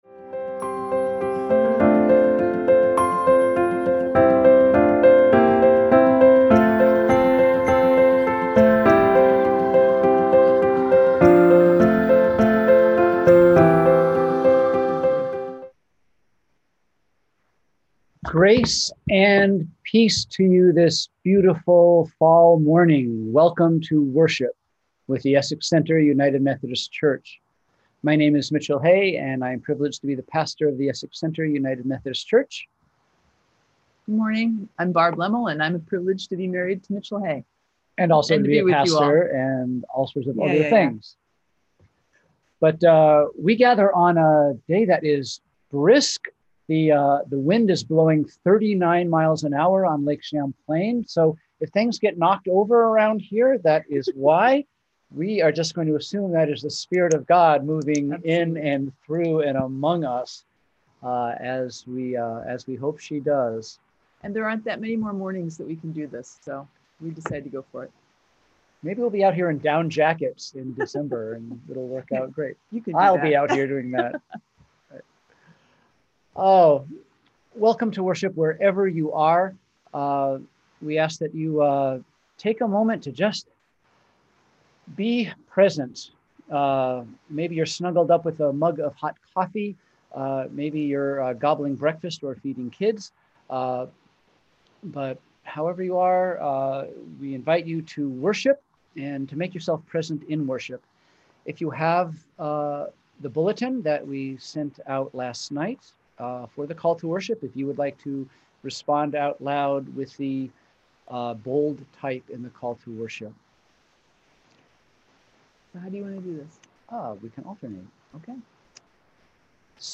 We held virtual worship on Sunday, September 13, 2020 at 10am.